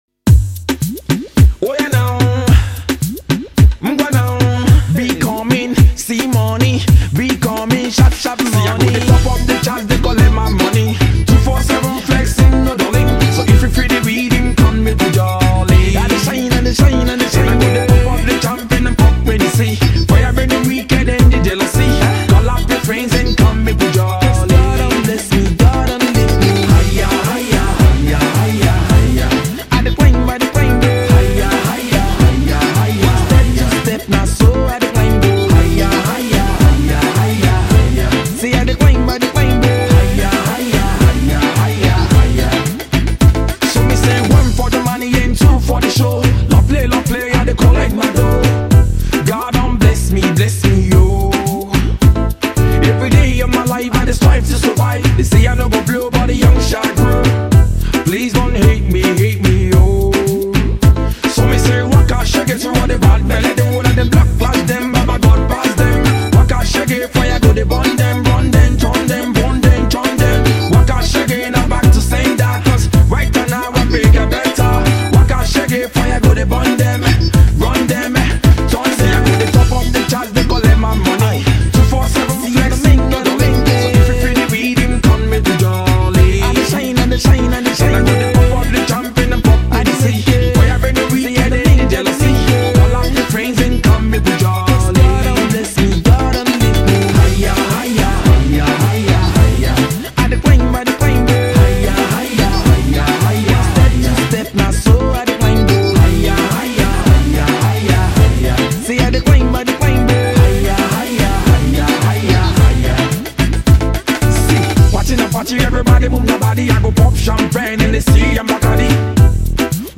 hiplife tune